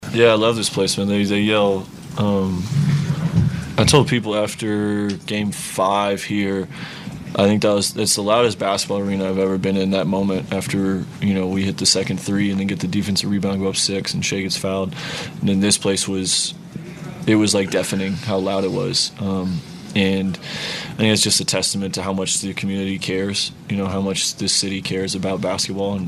Thunder guard Alex Caruso says the crowd helped the effort.